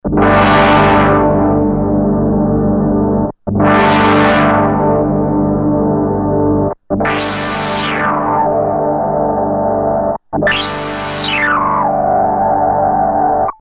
No external effects added
Filter sounds analog because it is analog. No emulation here, one oscillator:
Monophonic. Fast filter envelope and changing settings, resonant bass (1.22MB)